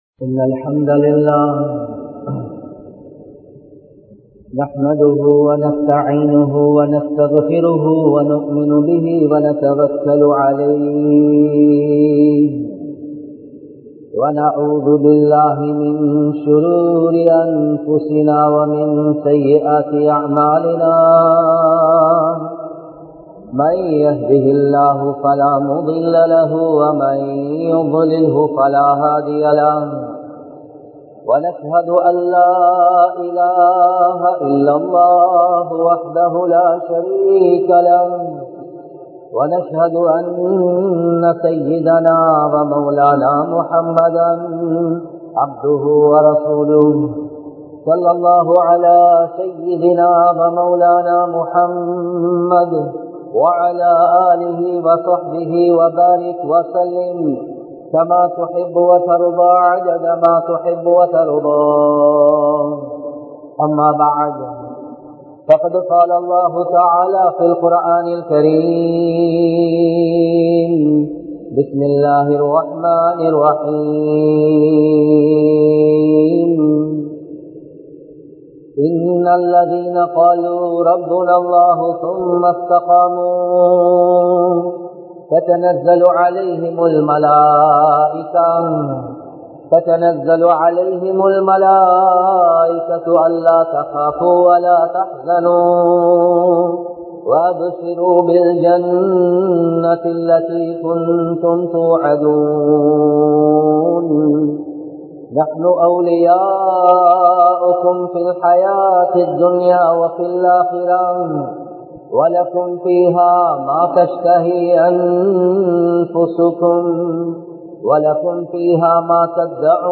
வாழ்க்கையின் யதார்த்தம் | Audio Bayans | All Ceylon Muslim Youth Community | Addalaichenai